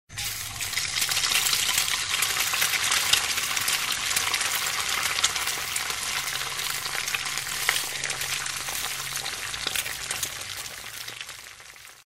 Звуки гриля